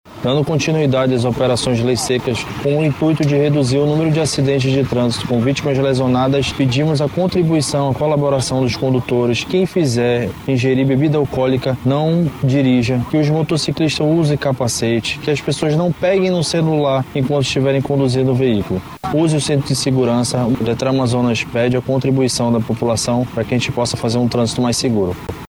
Durante as operações, três motoristas foram presos por dirigirem sob a influência de álcool, conforme previsto no Art. 306 do Código de Trânsito Brasileiro (CTB), como explica o diretor-presidente do Detran-AM, David Fernandes.